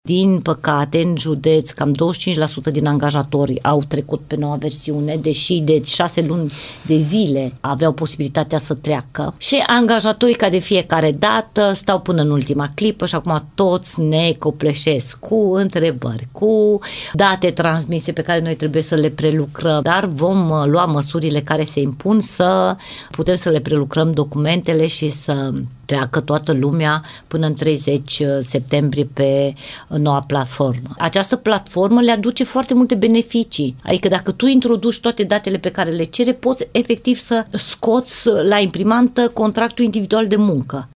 ITM Timiș va prelungi programul de lucru pentru a face față numărului mare de înregistrări, spune inspectorul șef Ileana Mogoșanu.